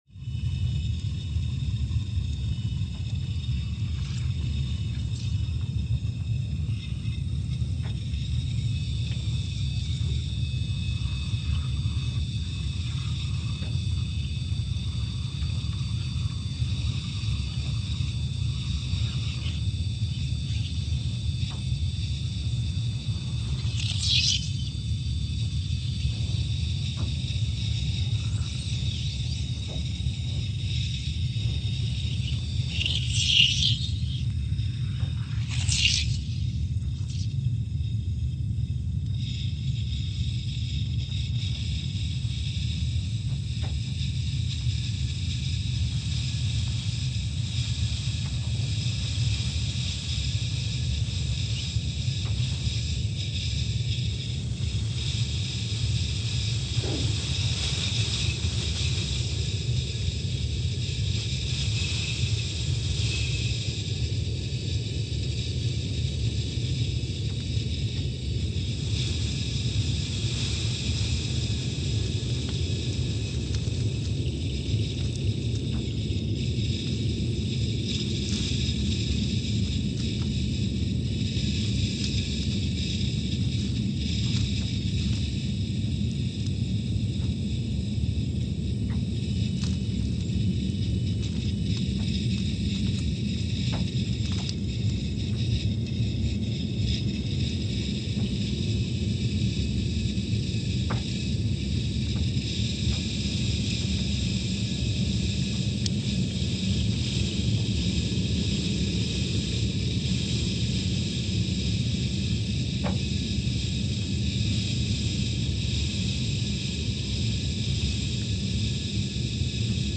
Scott Base, Antarctica (seismic) archived on July 6, 2022
Station : SBA (network: IRIS/USGS) at Scott Base, Antarctica
Sensor : CMG3-T
Speedup : ×500 (transposed up about 9 octaves)
SoX post-processing : highpass -2 90 highpass -2 90